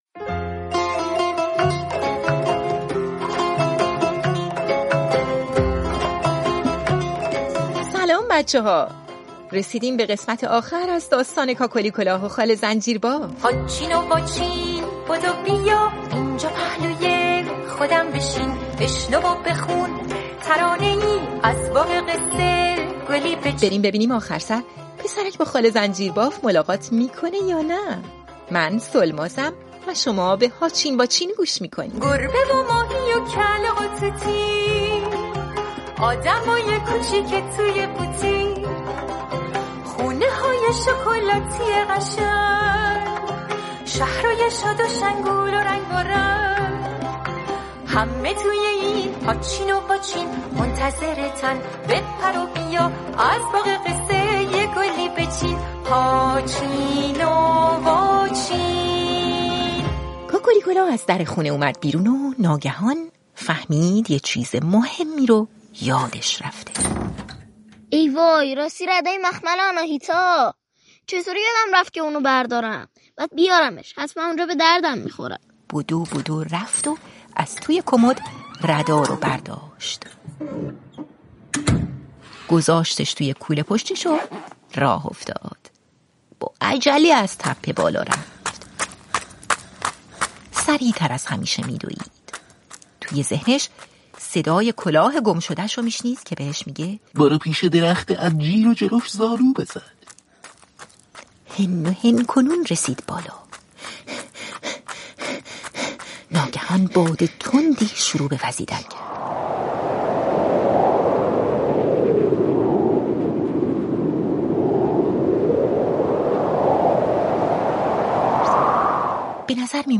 پادکست «هاچین واچین» اولین کتاب صوتی رادیوفردا، مجموعه قصه‌های کودکان است.
در ضمن توی این قسمت چند ثانیه از یک سخنرانی علمی رو می‌شنوید که داستان جالبی داره.